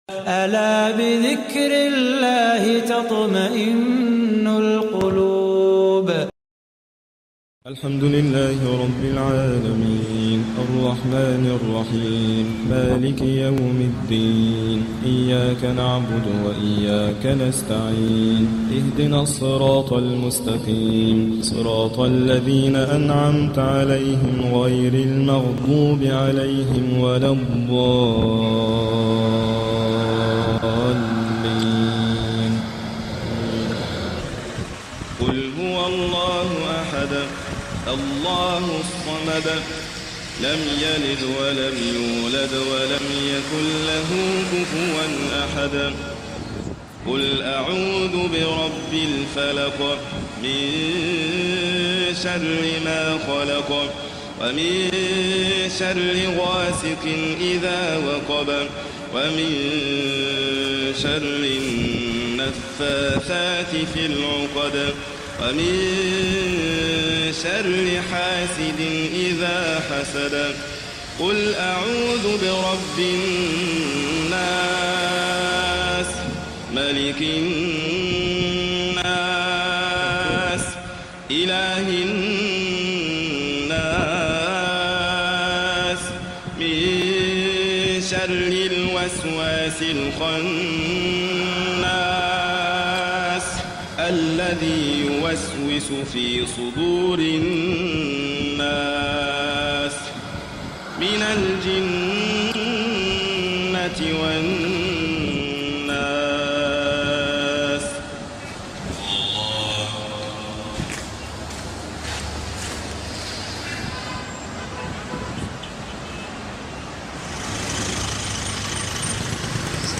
Muslims praying in masjid al Qaed Ibrahim in Alexandria, Egypt.